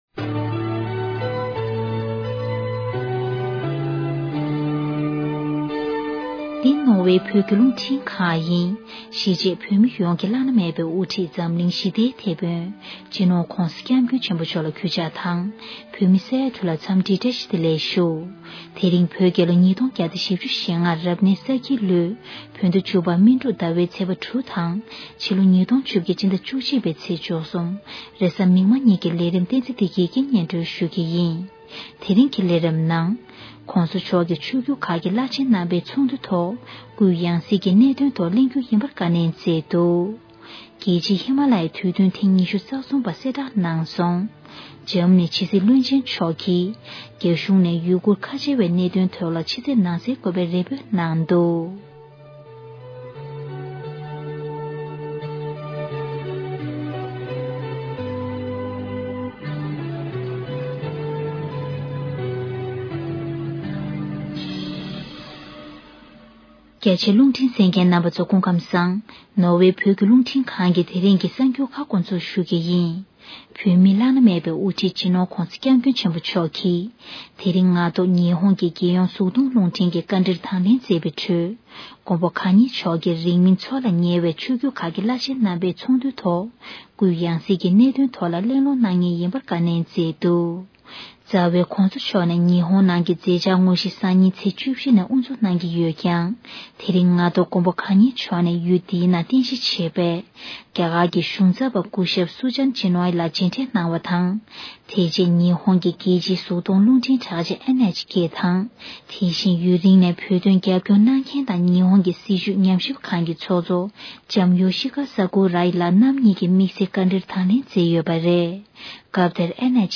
VOT News Archives of the Tibet - Voice of Tibet is an independent radio station based in Norway transmitting shortwave radio programs in the Tibetan language as well as Mandarin Chinese.